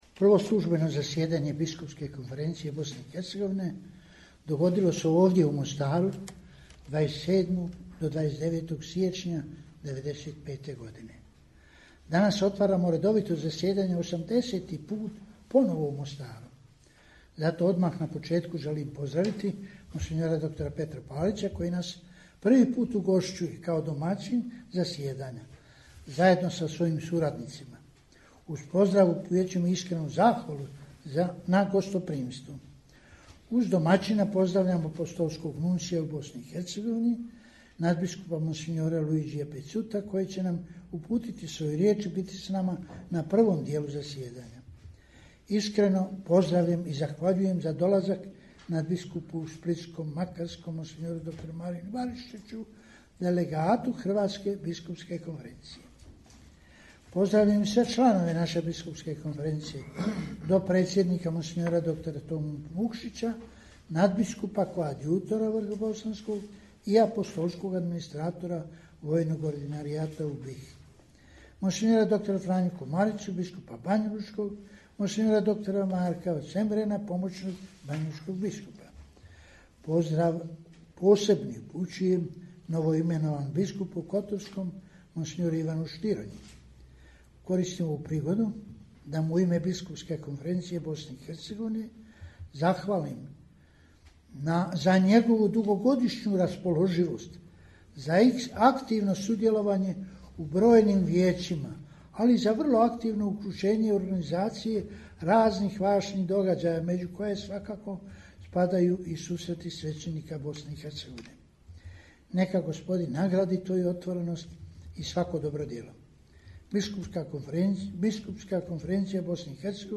AUDIO: POZDRAV KARDINALA PULJIĆA NA POČETKU 80. REDOVITOG ZASJEDANJA BISKUPSKE KONFERENCIJE BOSNE I HERCEGOVINE
Na početku 80. redovitog zasjedanja Biskupske konferencije Bosne i Hercegovine, koje je otvoreno u prijepodnevnim satima u četvrtak, 18. ožujka 2021. u prostorijama Biskupskog ordinarijata u Mostaru, svoj pozdrav uputio je predsjednik BK BiH kardinal Vinko Puljić, nadbiskup metropolit vrhbosanski.